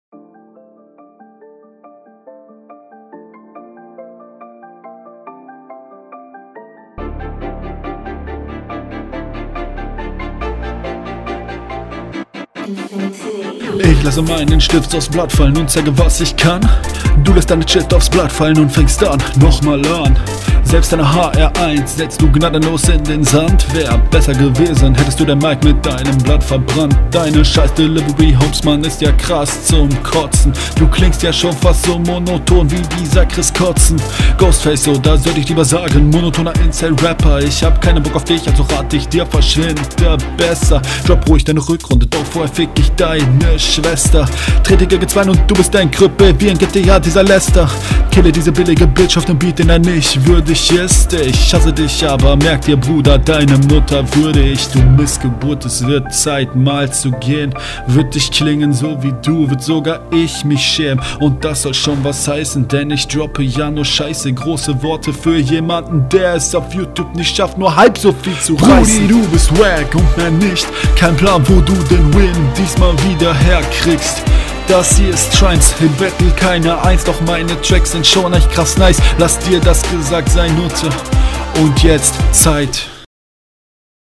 Der Mix ist zwar immer noch bad, aber viel viel besser als vorher. danke.